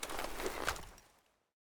Spas-12 Reanimation / gamedata / sounds / weapons / spas / draw.ogg.bak
draw.ogg.bak